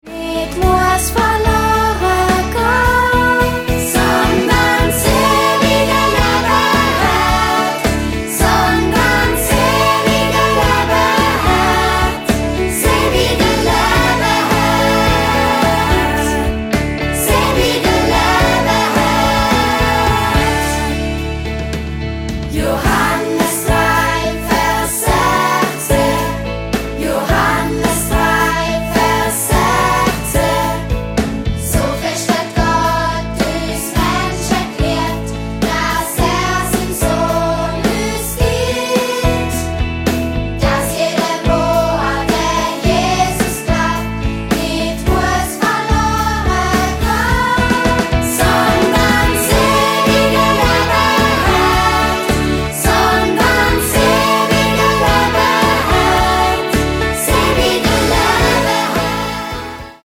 20 Bibelverssongs
24 Bibelverse peppig und eingängig vertont